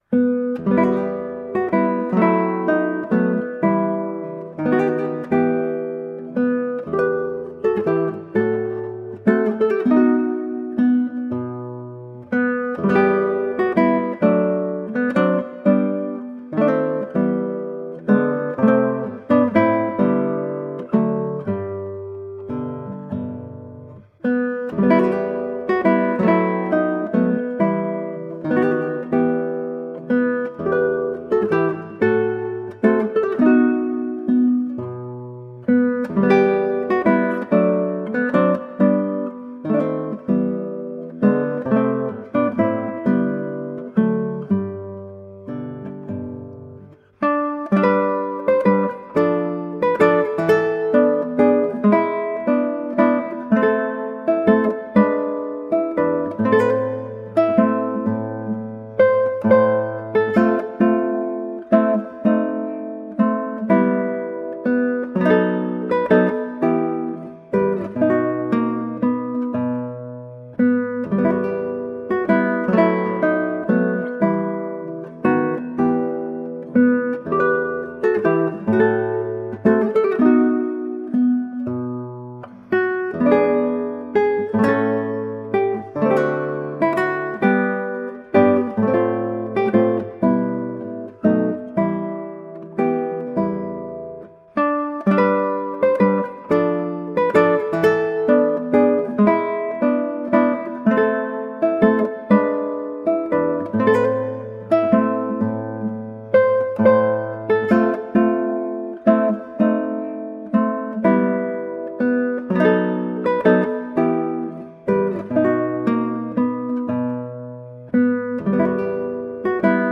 Colorful classical guitar.
Instrumental
Classical Guitar